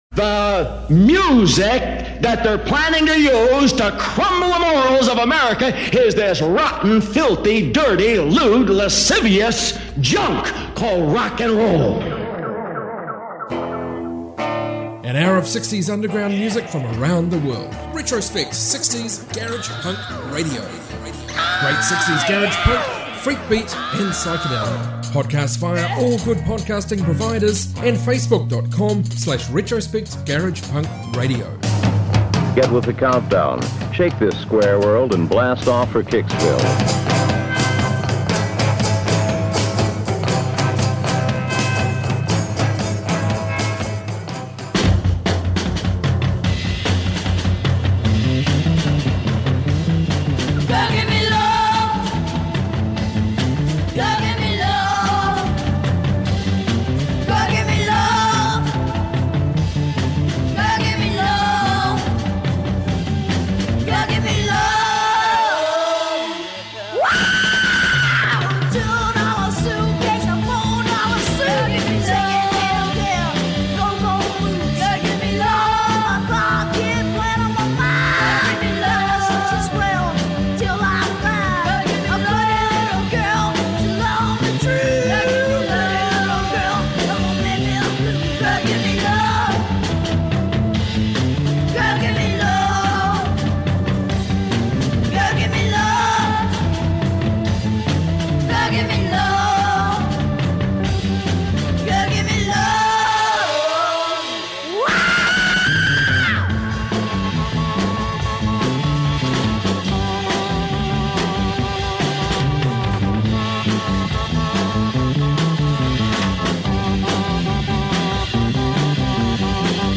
Obscure global 60s garage